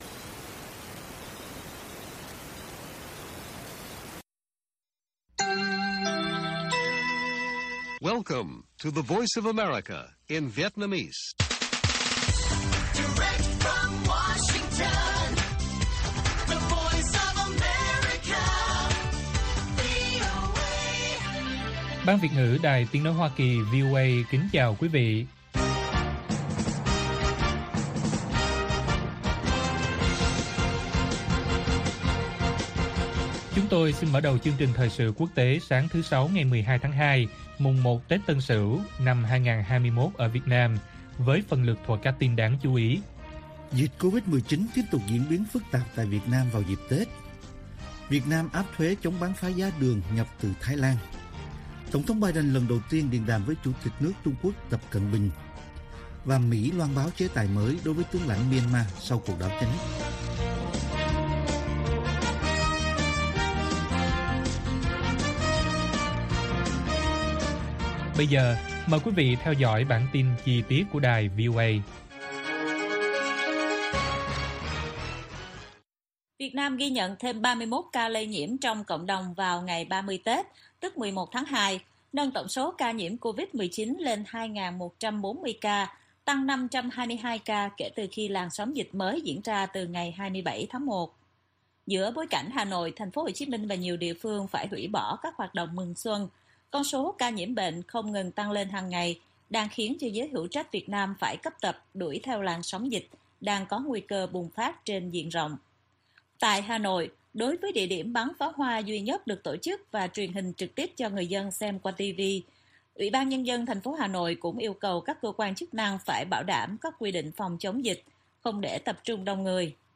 Bản tin VOA ngày 12/2/2021